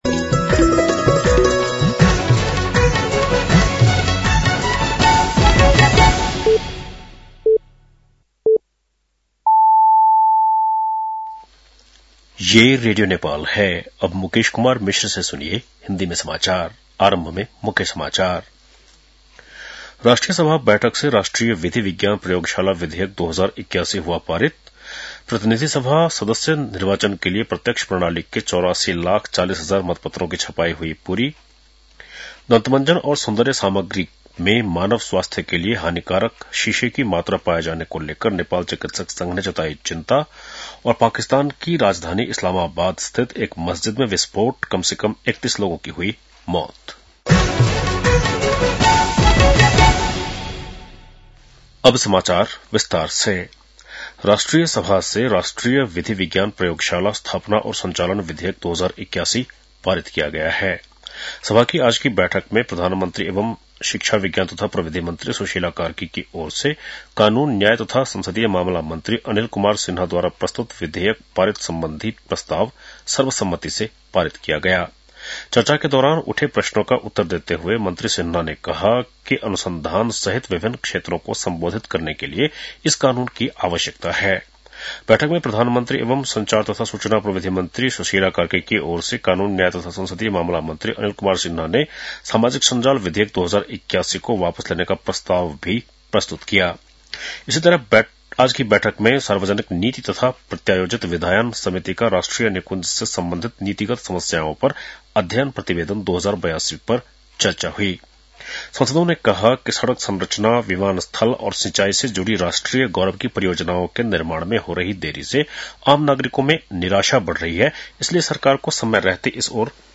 बेलुकी १० बजेको हिन्दी समाचार : २३ माघ , २०८२